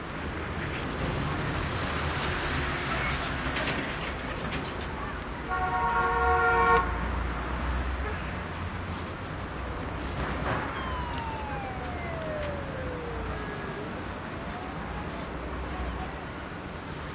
add ambient audio track
downtown.ogg